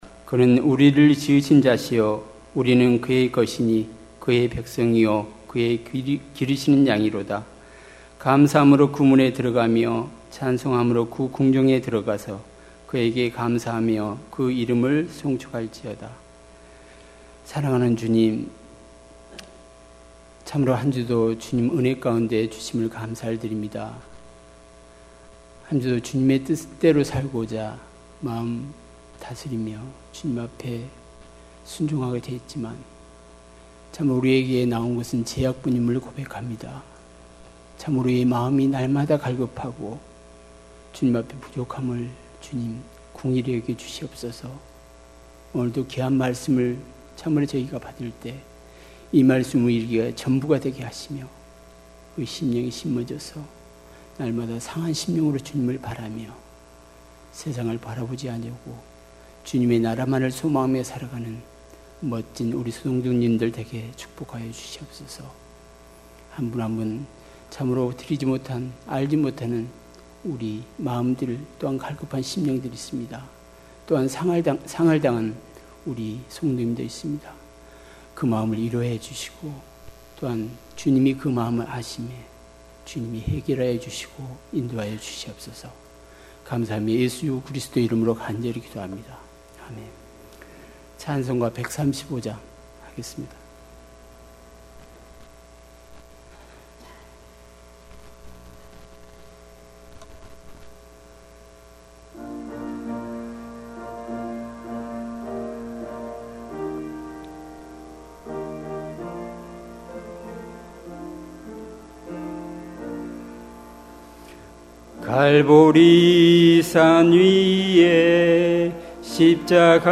특별집회 - 갈라디아서 2장 20절